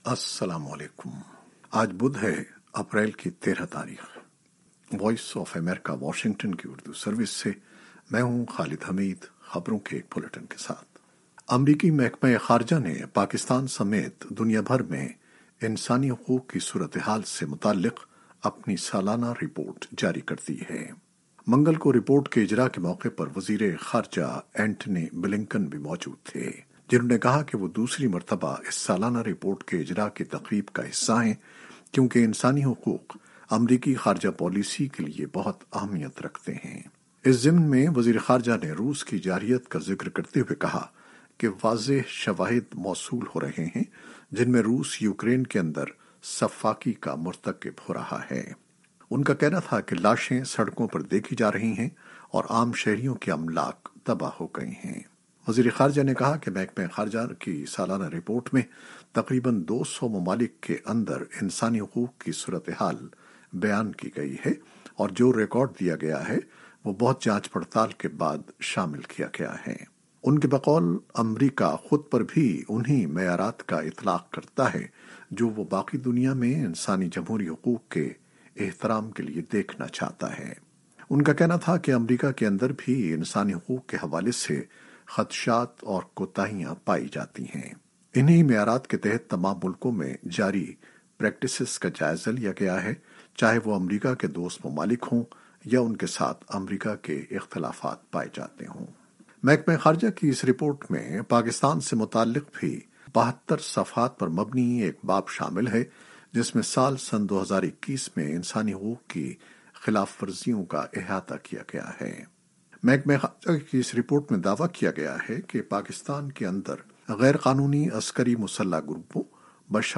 نیوز بلیٹن 2021-13-04